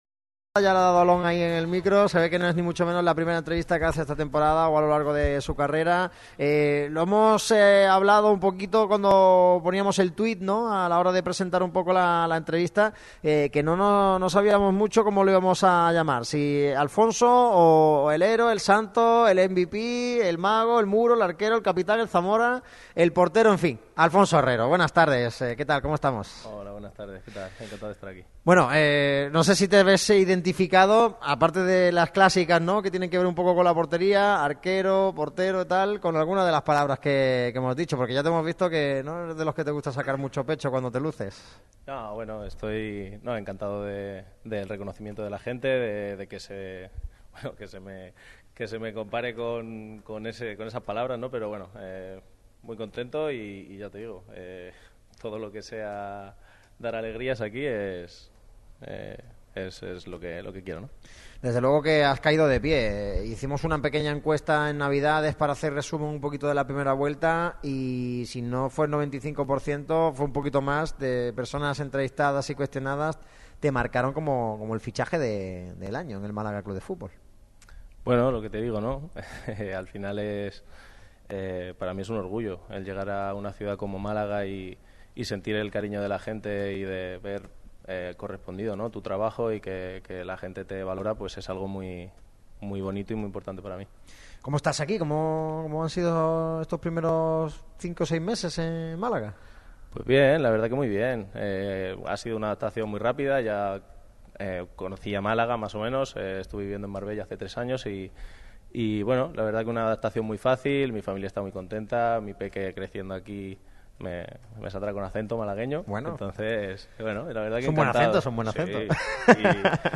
El guardameta boquerón ha pasado por el micrófono rojo de Radio MARCA Málaga para estrenar las entrevistas de futbolistas del Málaga en este 2024.
El toledano, muy dicharachero, ha comentado sobre todos los temas que engloban al conjunto de Martiricos. Su adaptación personal, el rendimiento del equipo, sus paradas salvadoras o su futuro.